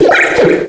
Cri de Doudouvet dans Pokémon Noir et Blanc.